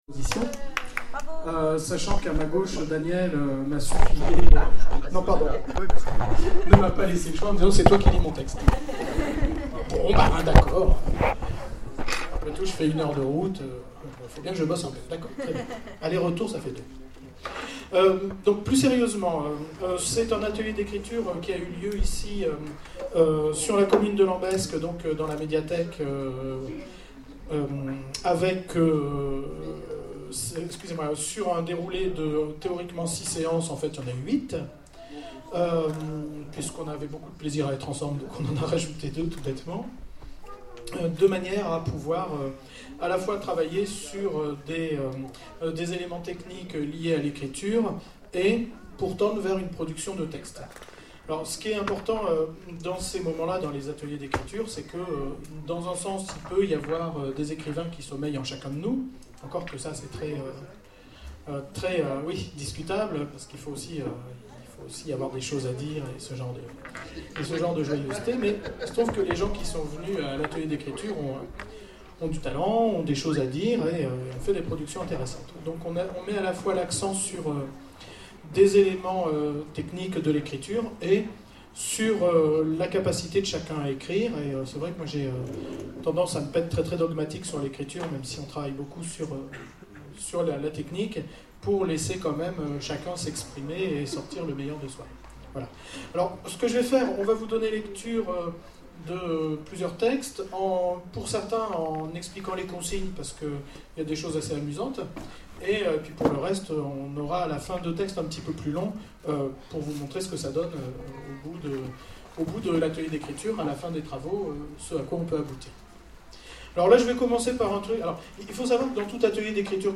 Festival Autres Mondes : Lecture des nouvelles du concours d'écriture